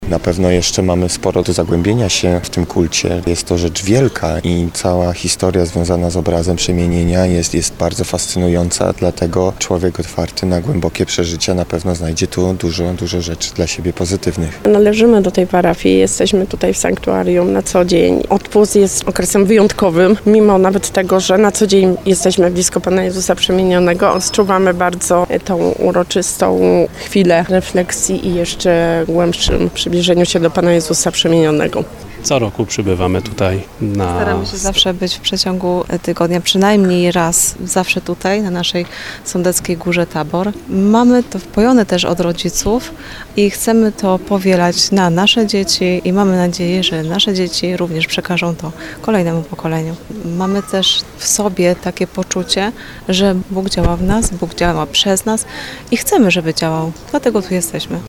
9sonda_dlugie.mp3